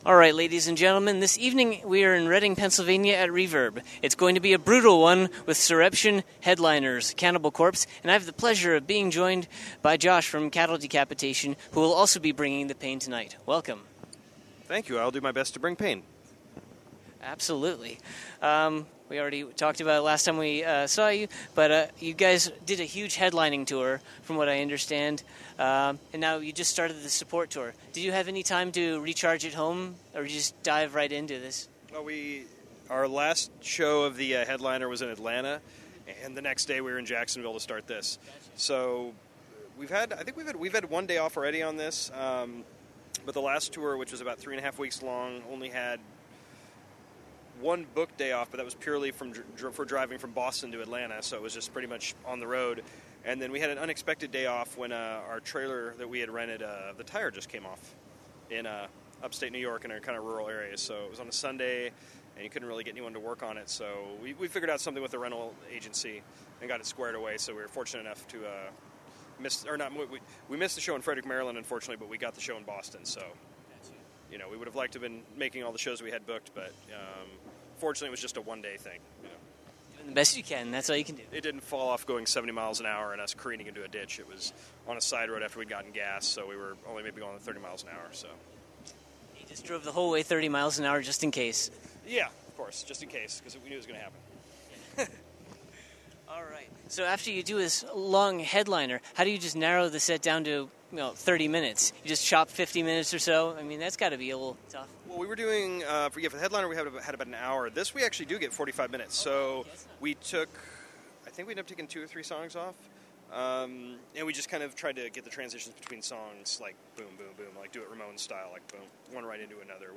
Exclusive: Cattle Decapitation and Cannibal Corpse Interview